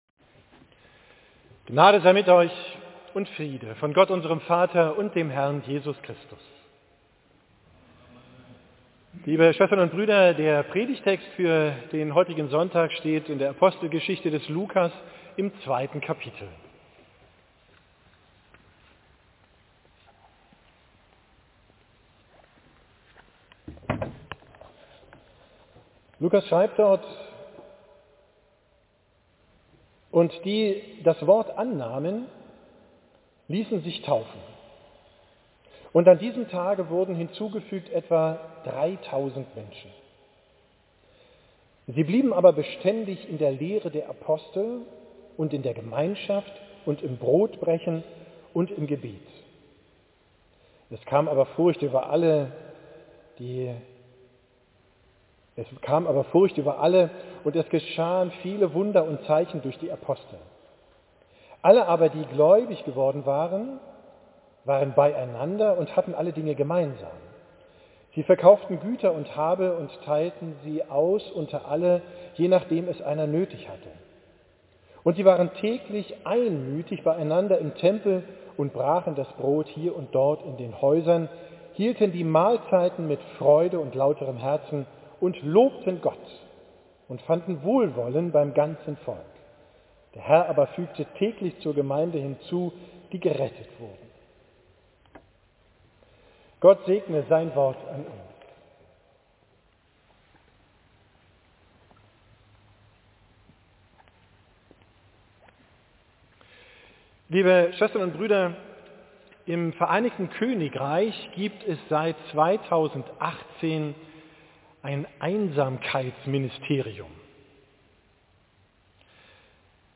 Predigt vom 7. Sonntag nach Trinitatis, 23. VII 23